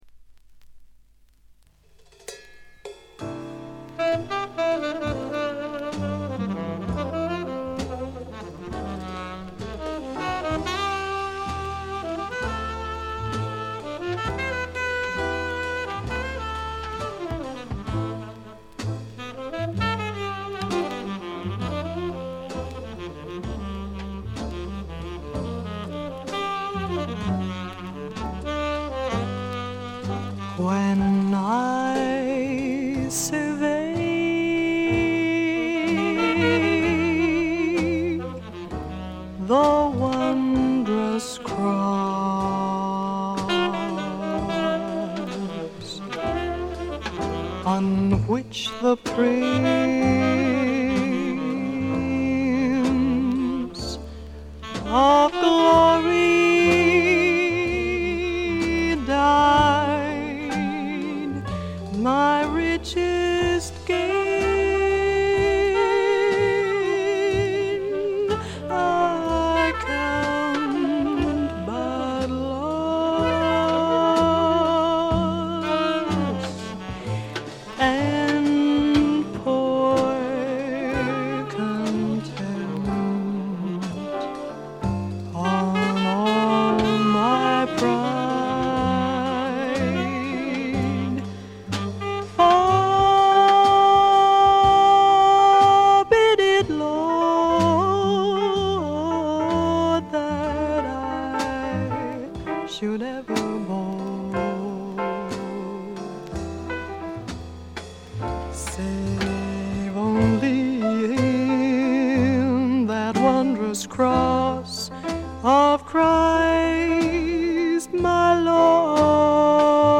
プレスが良くないのか見た目より悪くて、全体にバックグラウンドノイズ、チリプチ多め大きめ、散発的なプツ音少々。
存在感のあるアルトヴォイスがとてもいい味をかもし出して、個人的にも大の愛聴盤であります。
試聴曲は現品からの取り込み音源です。